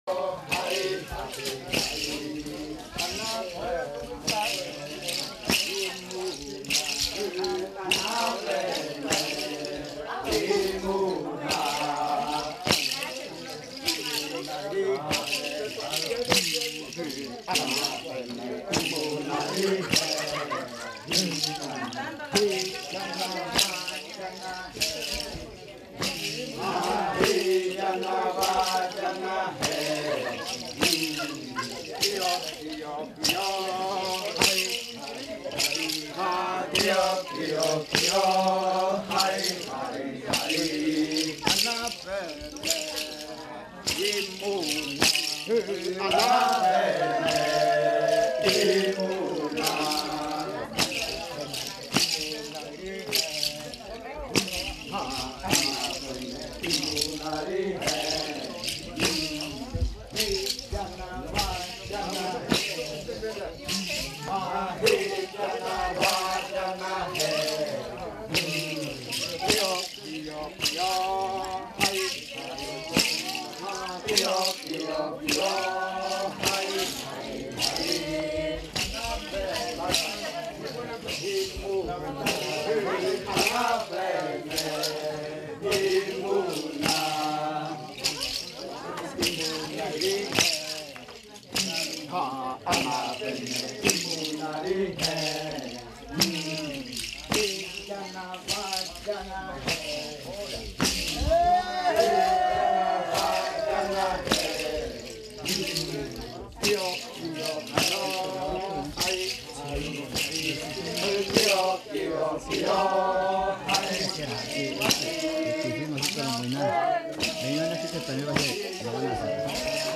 Canto de cierre de la variante muinakɨ
Leticia, Amazonas
Canto de amanecida de la variante muinakɨ
con el grupo de cantores bailando en la Casa Hija Eetane. Este canto fue interpretado en el baile de clausura de la Cátedra de lenguas "La lengua es espíritu" de la UNAL Sede Amazonia.
with the group of singers dancing at Casa Hija Eetane.